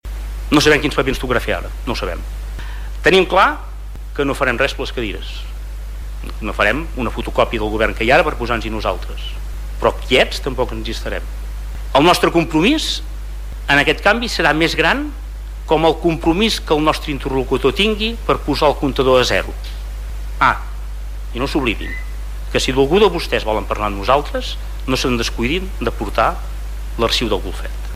Com era previsible, el trencament del pacte de govern a Palafrugell entre el PSC i CiU ha marcat la sessió plenària d’aquest dimarts al vespre.
Per la seva part, el portaveu del grup municipal d’Esquerra Republicana, Josep Piferrer, ha retret a socialistes i convergents que les desavinences eren evidents feia temps. De cara al futur, Piferrer obre la mà a acords, però amb condicions: